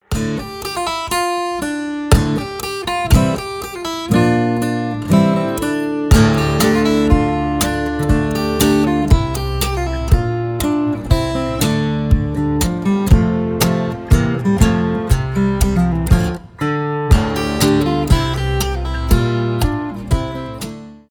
cover , гитара , акустика
без слов